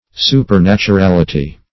Search Result for " supernaturality" : The Collaborative International Dictionary of English v.0.48: Supernaturality \Su`per*nat`u*ral"i*ty\, n. The quality or state of being supernatural.
supernaturality.mp3